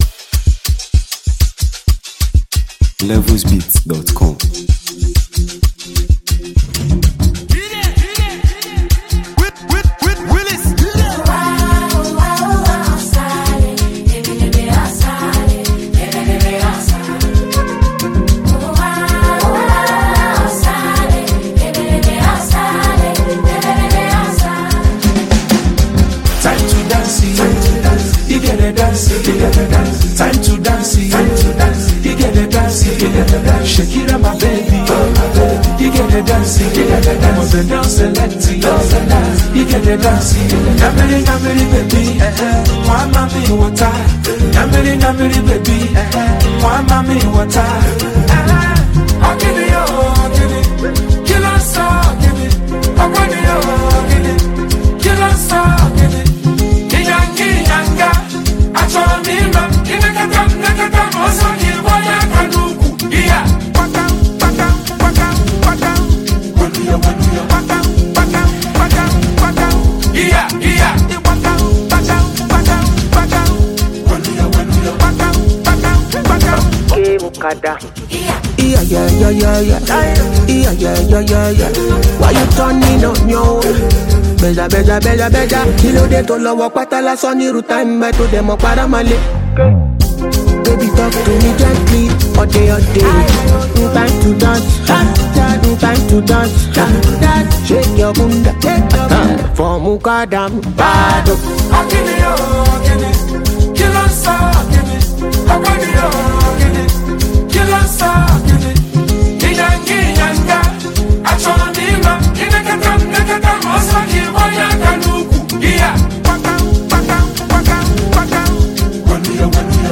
Highlife Anthem